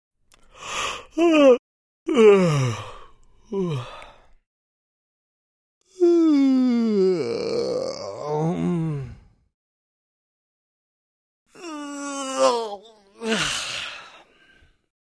На этой странице собраны натуральные звуки зевания и потягиваний — от утренних до вечерних.
Утреннее зевание мужчины